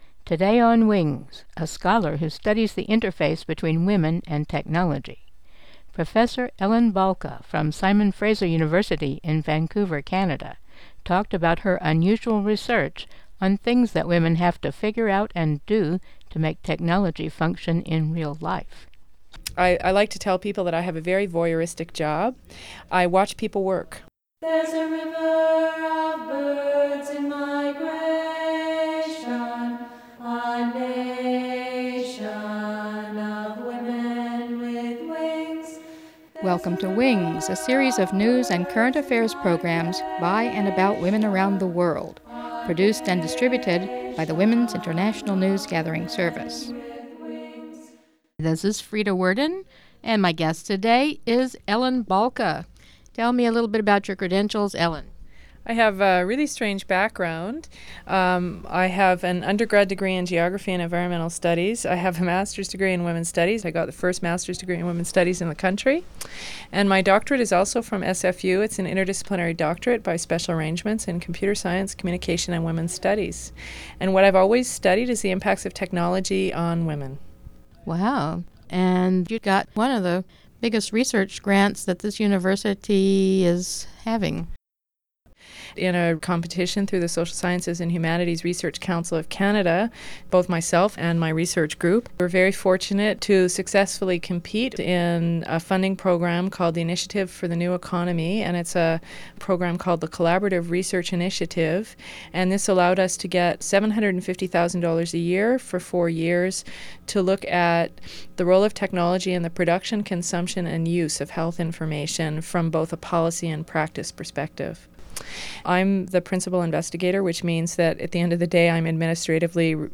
Interview
dubbed from minidisc